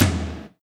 TOM08.wav